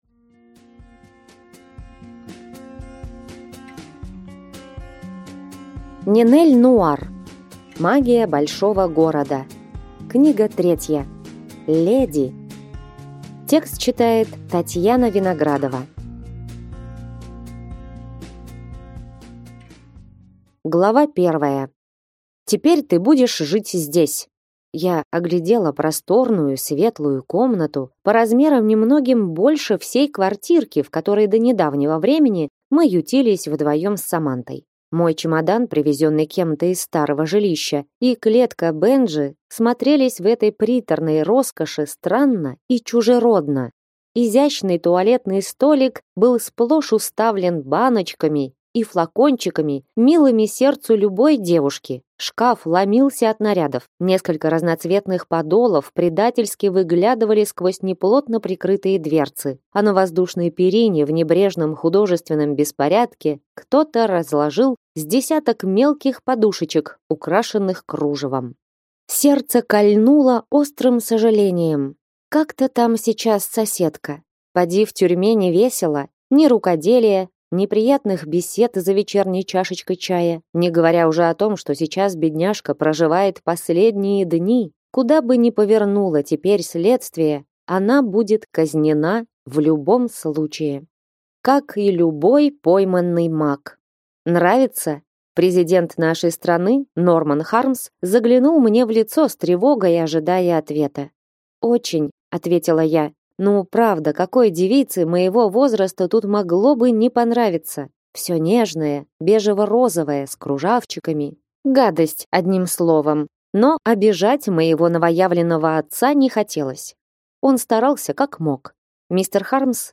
Аудиокнига Магия большого города. Книга 3. Леди | Библиотека аудиокниг